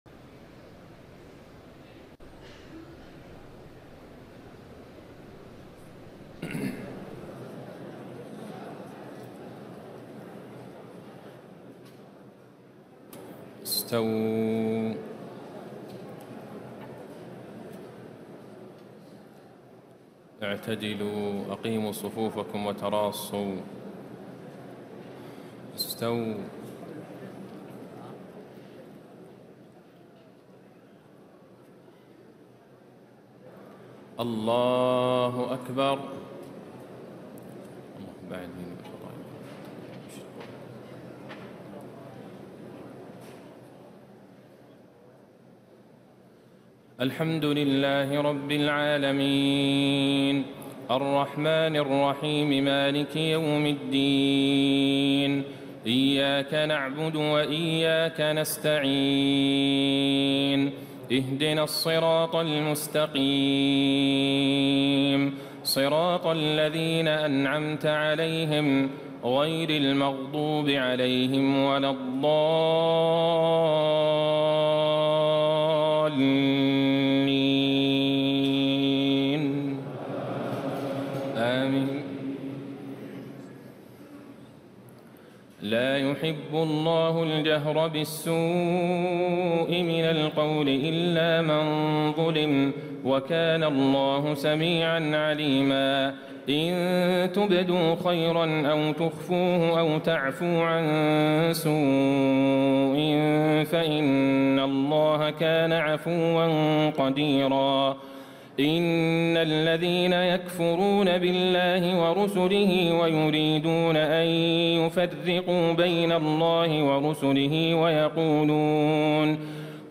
تهجد ليلة 26 رمضان 1436هـ من سورتي النساء (148-176) و المائدة (1-40) Tahajjud 26 st night Ramadan 1436H from Surah An-Nisaa and AlMa'idah > تراويح الحرم النبوي عام 1436 🕌 > التراويح - تلاوات الحرمين